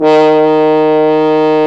Index of /90_sSampleCDs/Roland LCDP12 Solo Brass/BRS_French Horn/BRS_Mute-Stopped
BRS F.HORN0J.wav